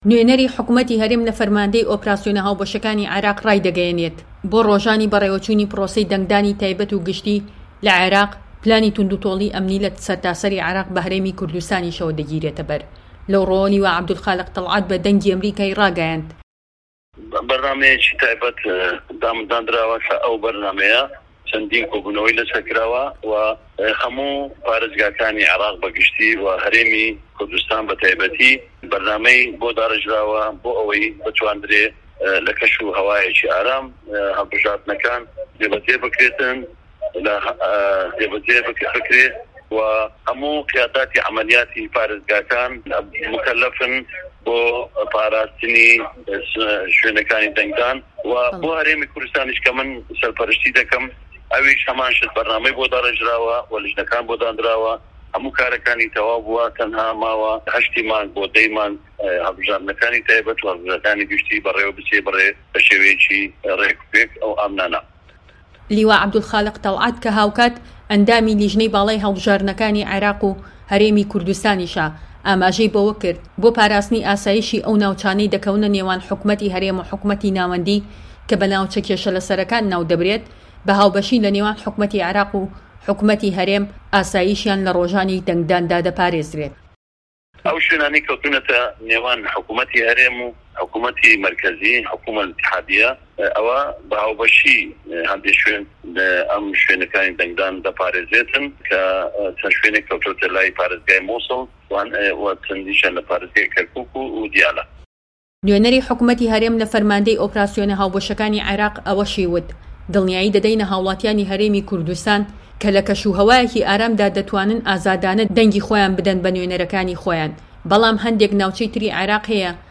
هه‌رێمه‌ کوردیـیه‌کان - گفتوگۆکان
لێدوانی لیوا عەبدولخالق تەڵعەت